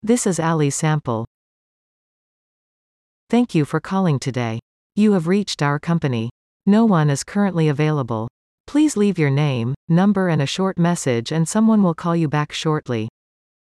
4. Ally / AI Voiceover VO004  $22 / up to 50 words
Let us have your script recorded in a female voice, using the latest in AI technology.